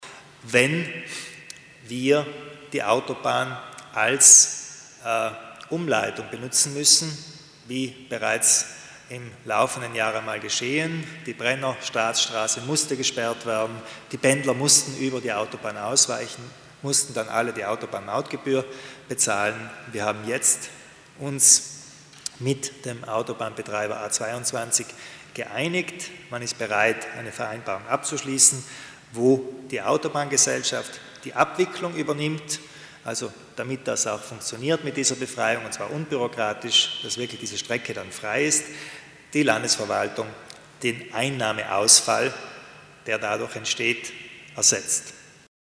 Landeshauptmann Kompatscher erläutert die Neuigkeiten bezüglich der Brennerautobahn